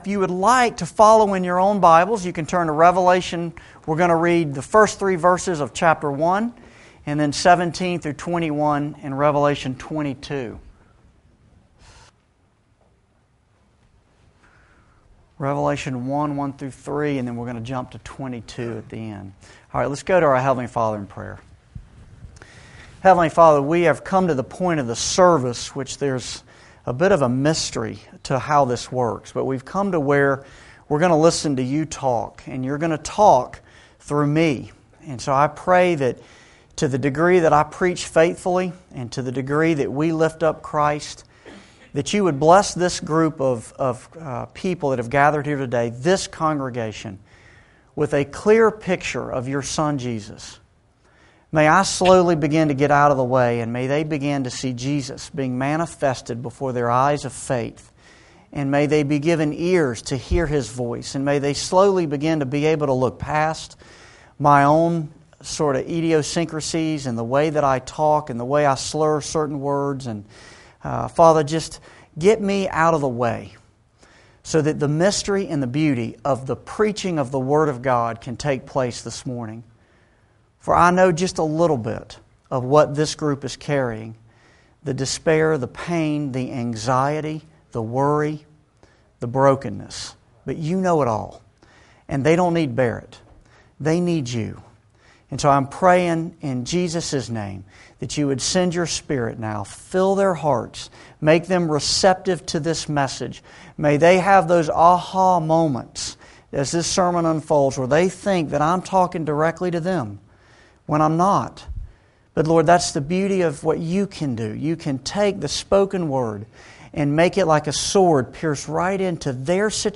Revelation-Sermon-4.mp3